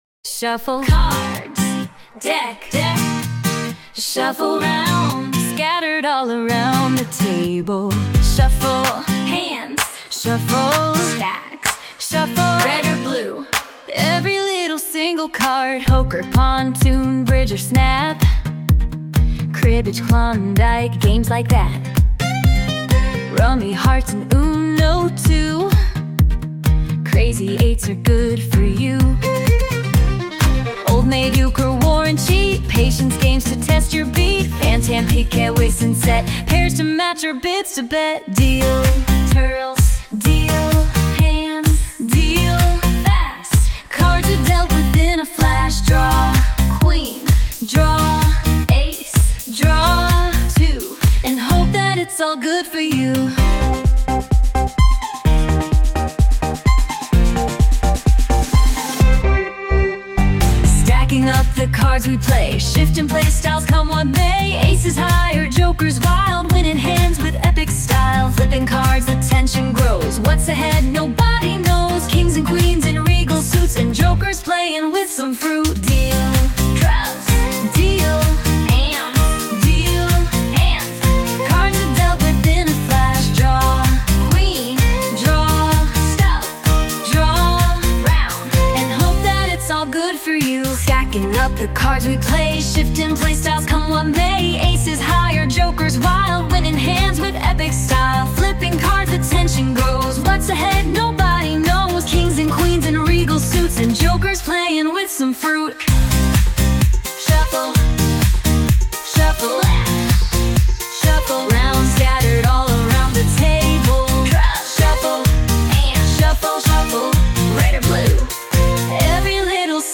Sound Imported : Enigmatically Zoned
Sung by Suno